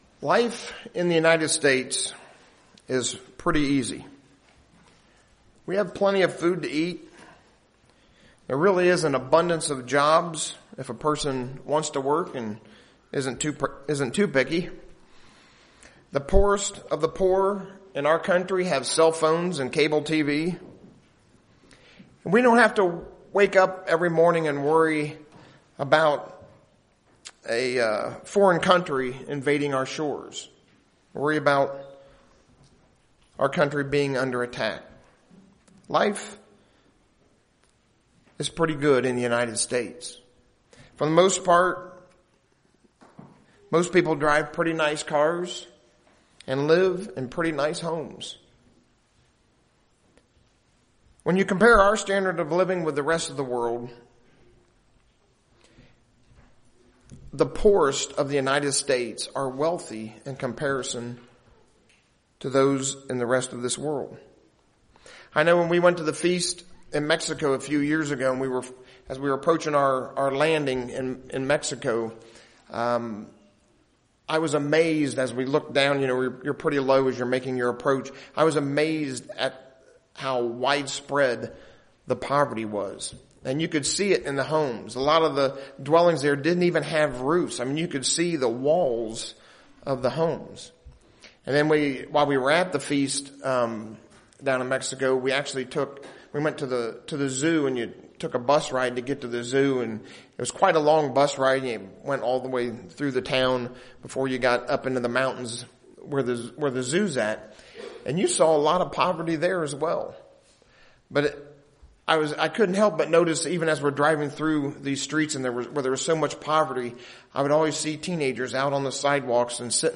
Given in Ft. Wayne, IN
Sabbath Services Studying the bible?